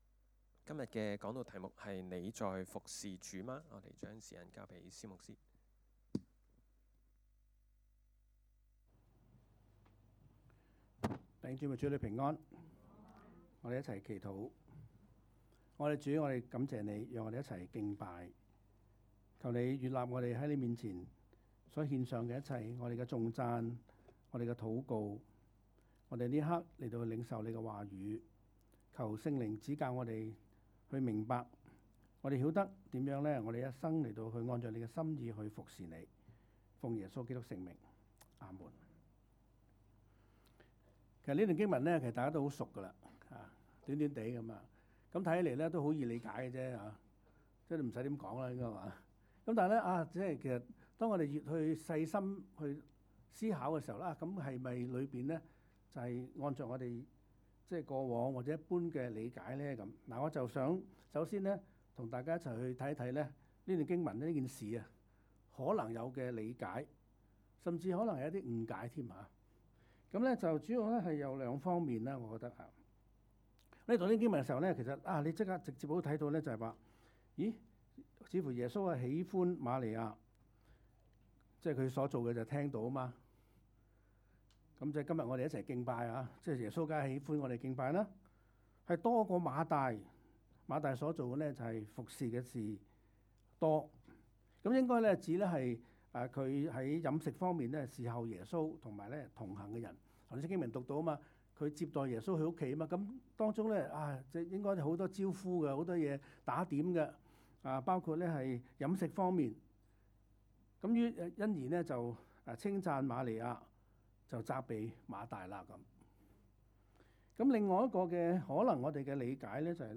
講道 ： 你在「服侍主」嗎?